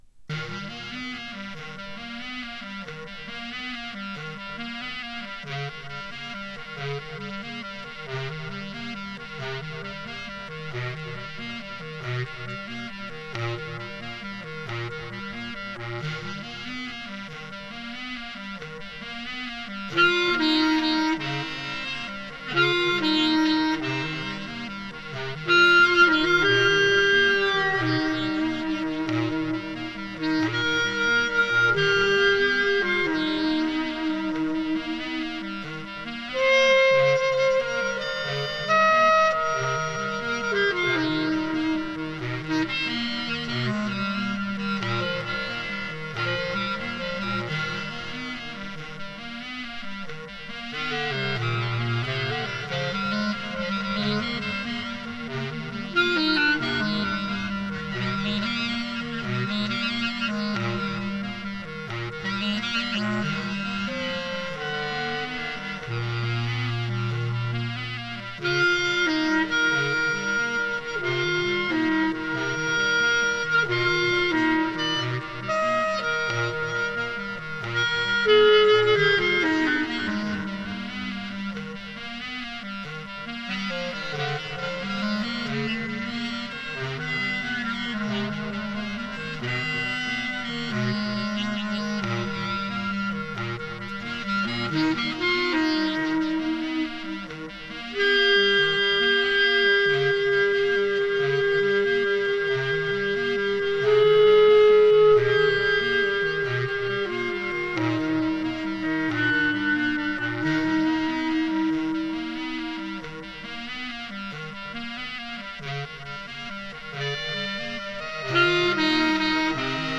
solo alto clarinet 3
Recorded live at home in Manhattan.
alto clarinet, samplers